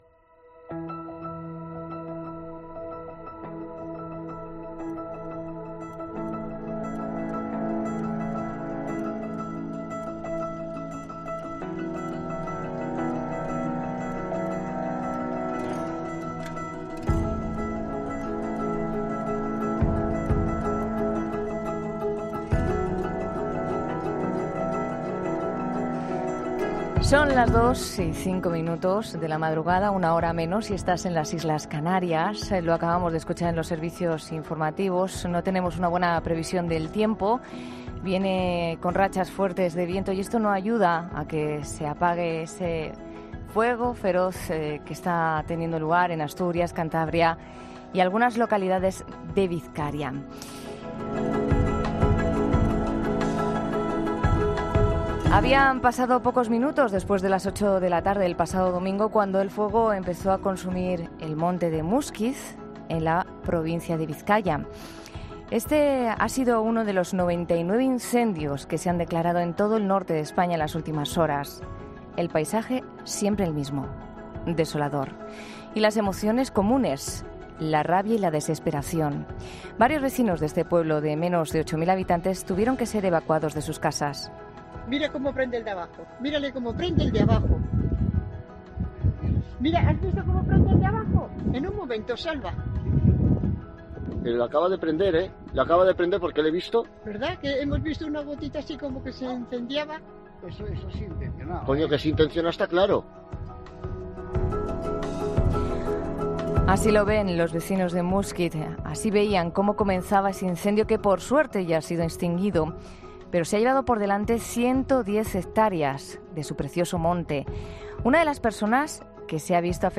Una vecina de Muskiz, en 'La Noche': “El fuego estaba allí y subía muy rápido”